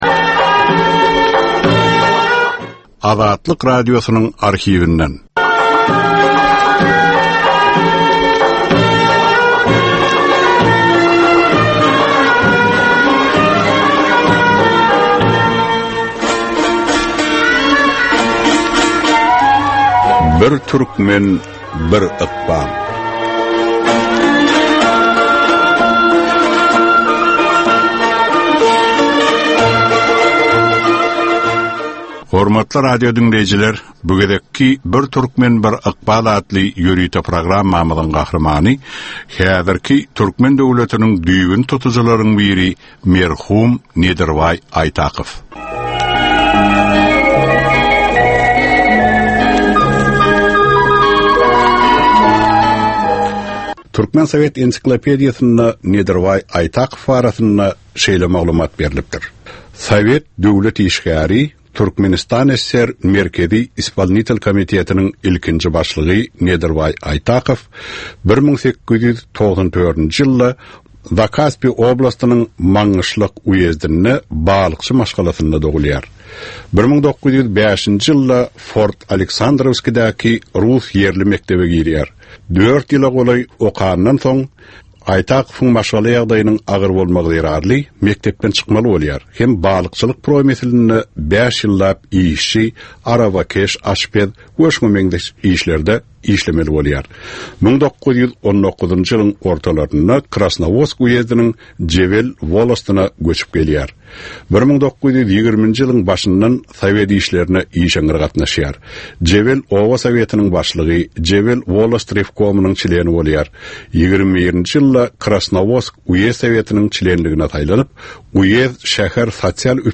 Türkmenistan we türkmen halky bilen ykbaly baglanyşykly görnükli şahsyýetleriň ömri we işi barada 55 minutlyk ýörite gepleşik. Bu gepleşikde gürrüňi edilýän gahrymanyň ömri we işi barada giňişleýin arhiw materiallary, dürli kärdäki adamlaryň, synçylaryň, bilermenleriň, žurnalistleriň we ýazyjy-sahyrlaryň pikirleri, ýatlamalary we maglumatlary berilýär.